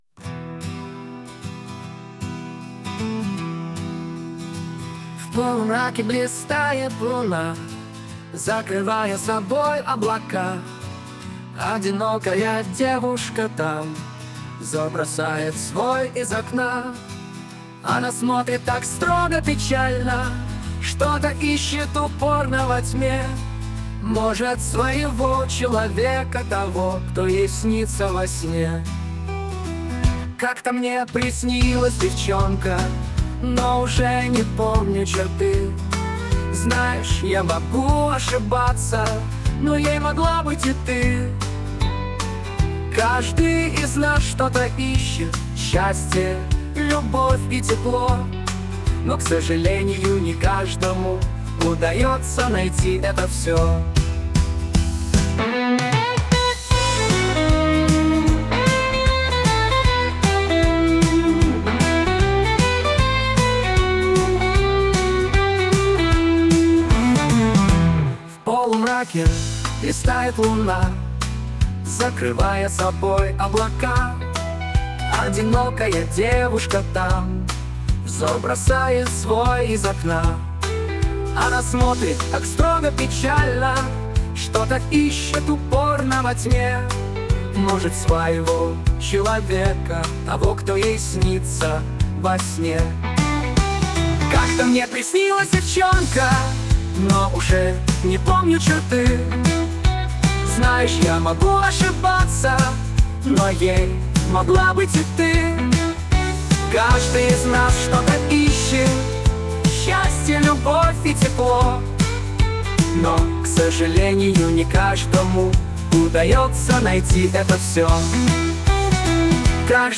Как то мне приснилась девчонка - стих озвучен в нейросети
Озвучка в нейросети: